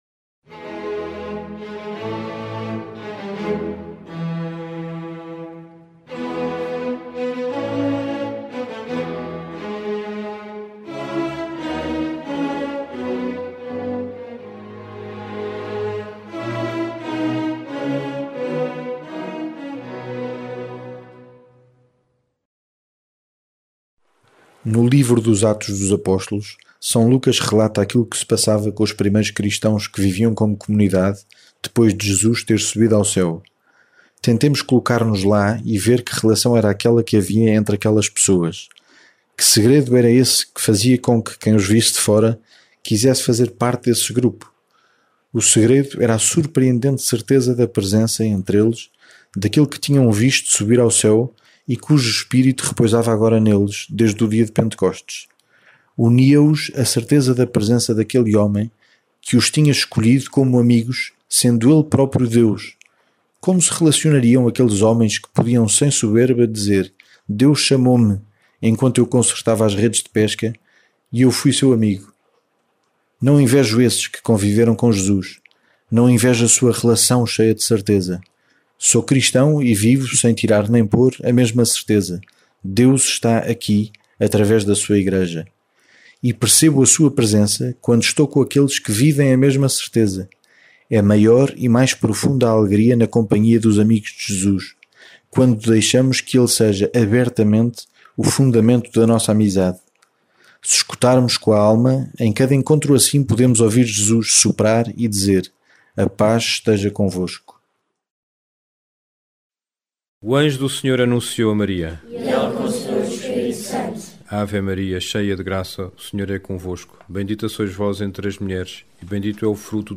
Angelus, Meditações Diárias